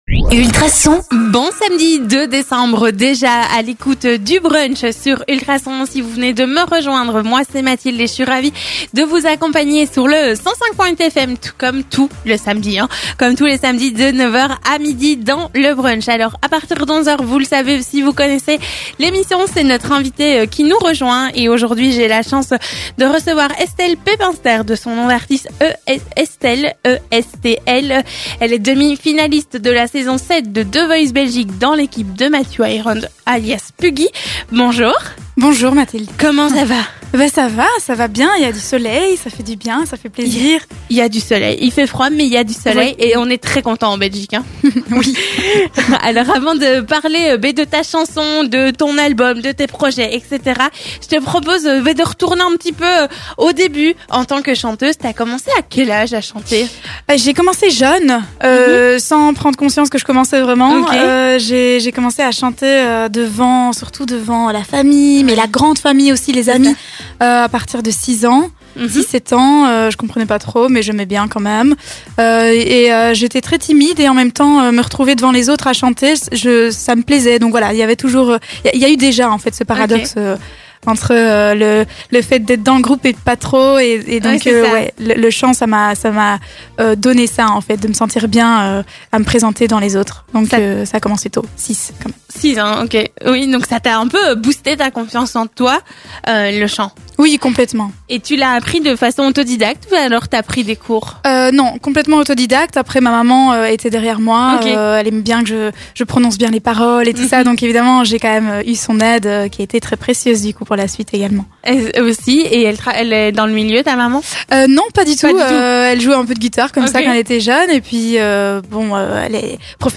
recevait la chanteuse